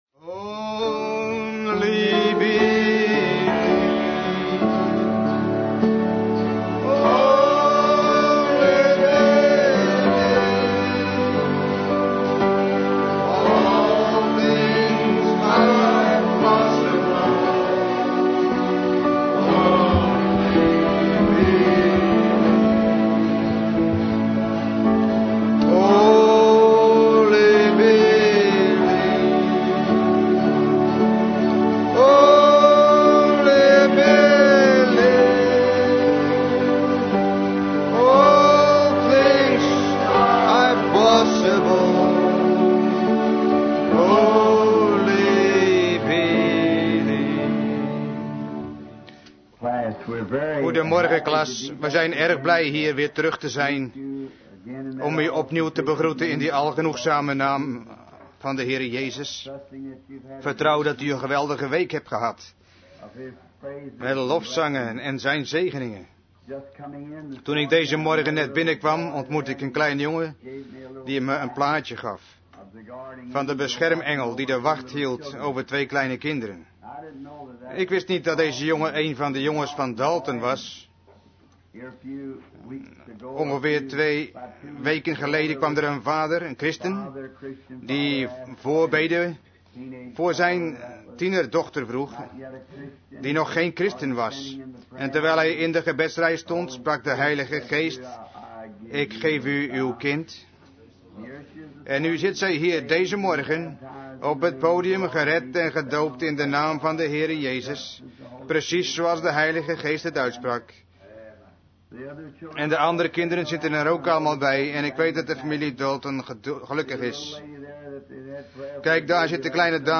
Vertaalde prediking "Adoption" door William Marrion Branham te Branham Tabernacle, Jeffersonville, Indiana, USA, 's ochtends op zondag 22 mei 1960